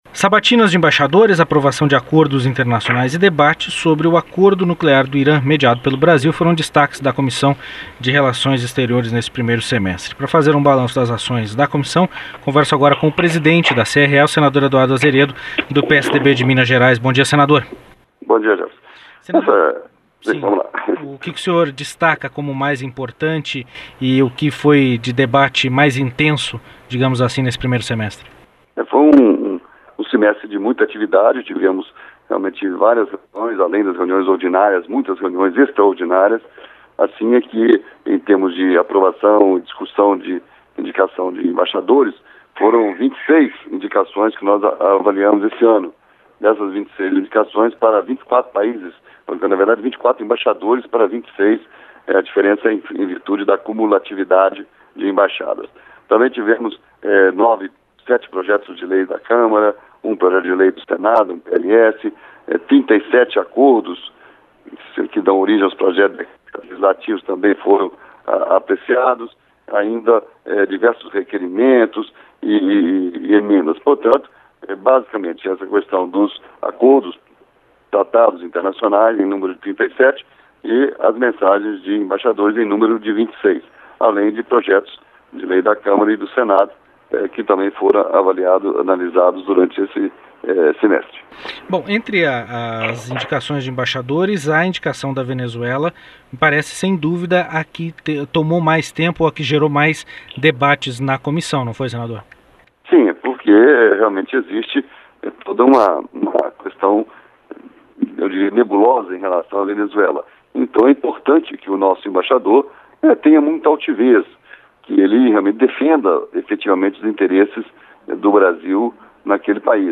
Entrevista com o senador Eduardo Azeredo (PSDB-MG), presidente da comissão.